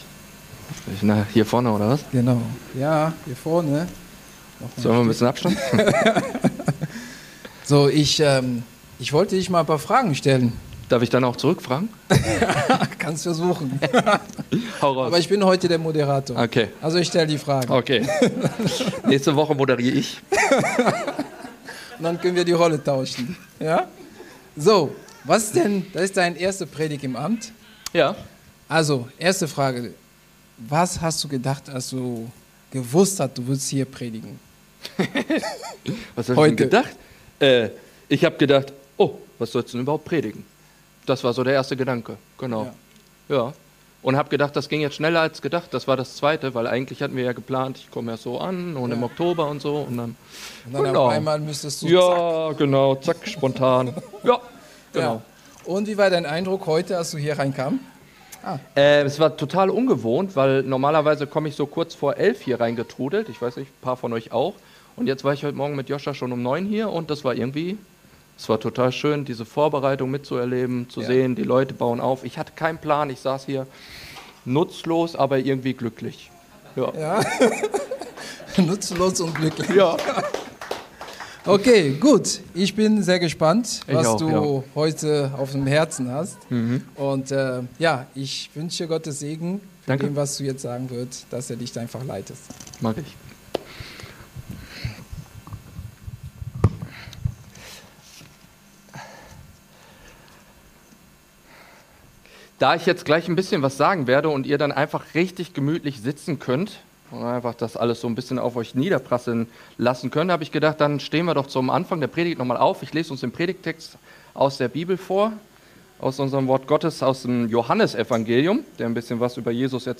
Die freimachenende, fehlerüberwindende Liebe von Jesus Christus ~ Predigt-Podcast von unterwegs FeG Mönchengladbach Podcast